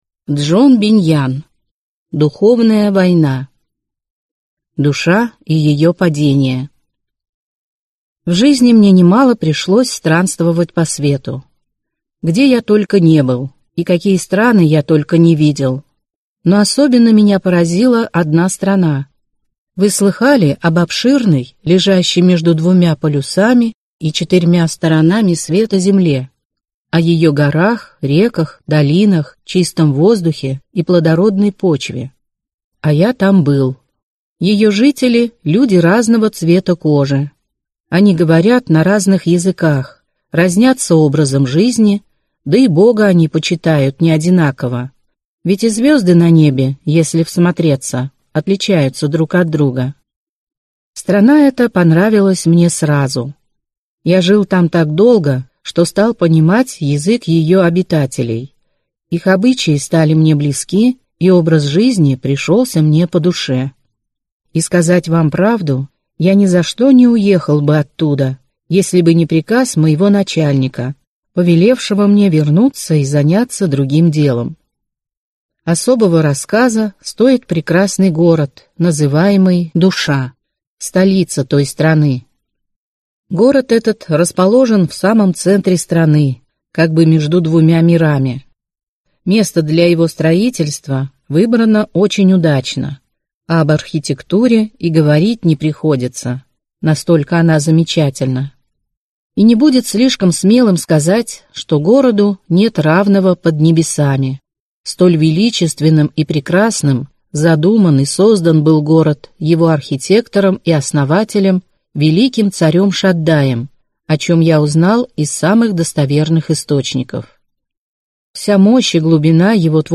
Aудиокнига Духовная война